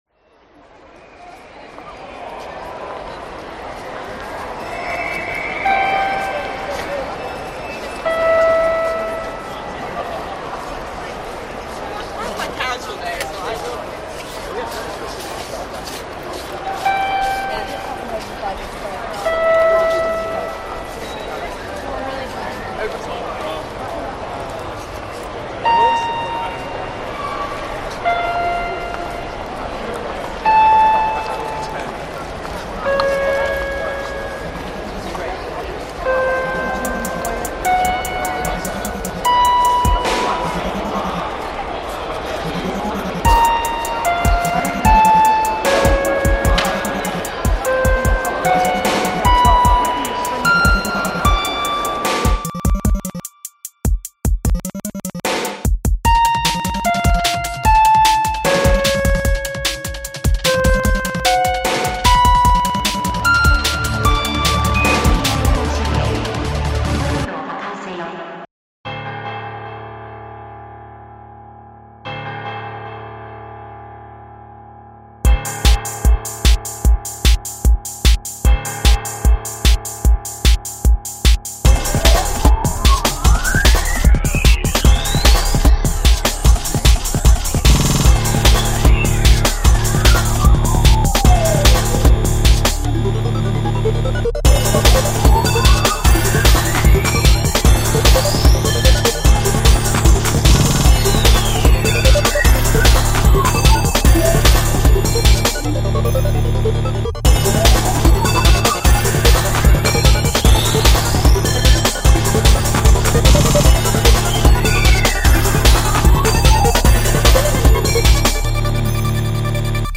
macOS Text-to-Speech Kyoko
AI Talk Seiren Voice Demo